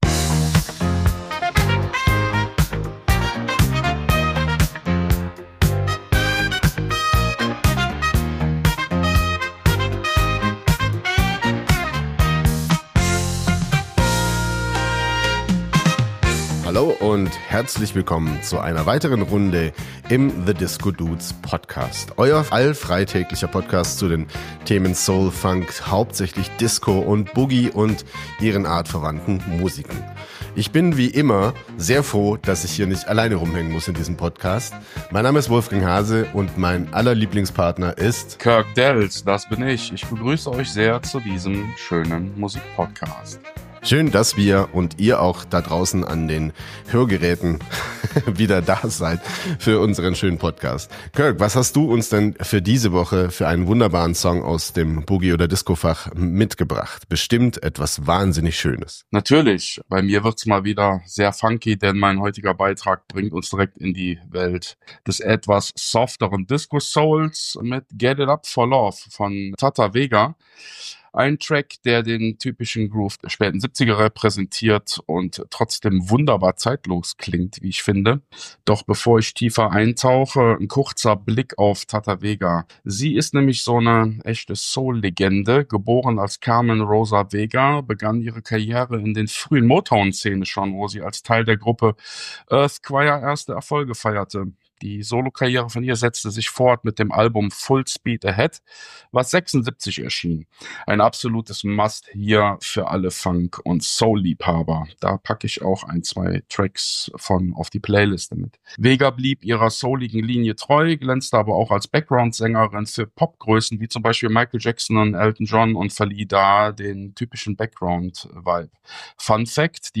Motown Disco Diva meets Florida Sunny Boy in Funk a Boogie